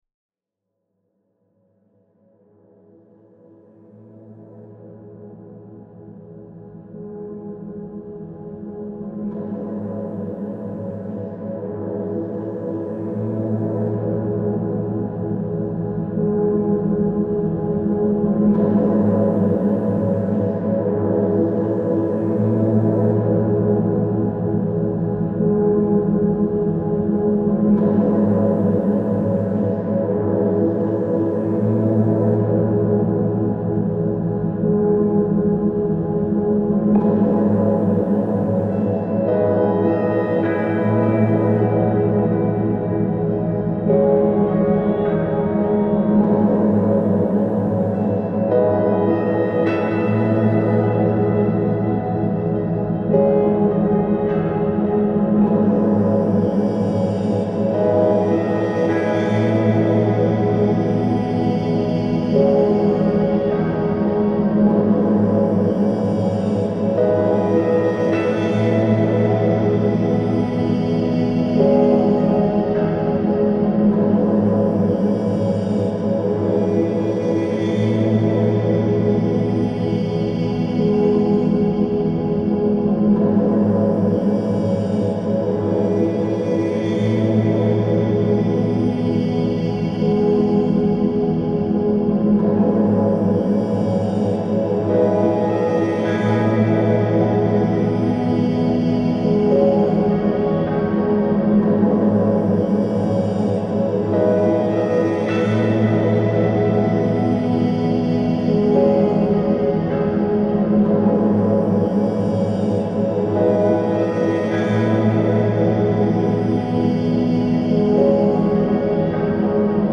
موسیقی بی کلام امبینت پیانو
موسیقی بی کلام آرام موسیقی بی کلام ساکت